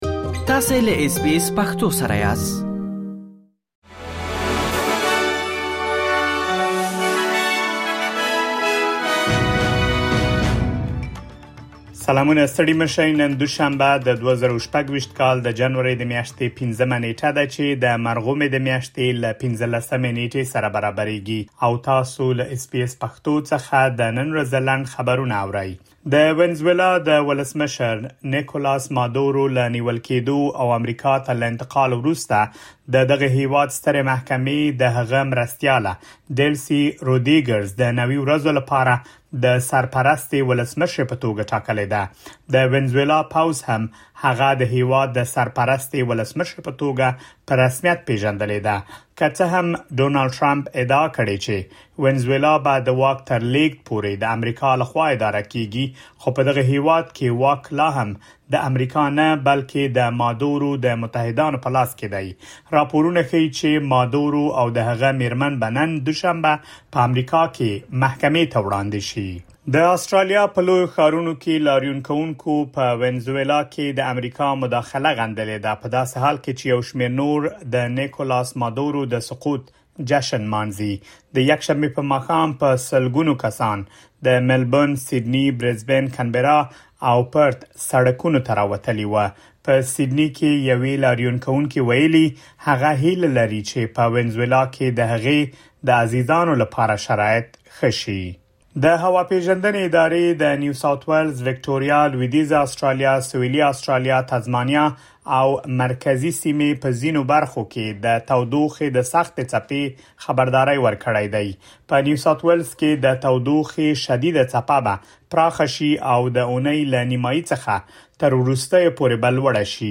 د اس بي اس پښتو د نن ورځې لنډ خبرونه |۵ جنوري ۲۰۲۶